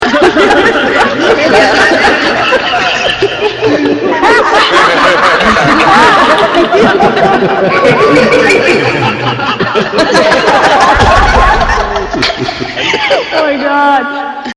Play Wakanda Laugh 3 Reverb - SoundBoardGuy
Play, download and share Wakanda laugh 3 reverb original sound button!!!!
wakanda-laughs-3-reverb_yVPUBIQ.mp3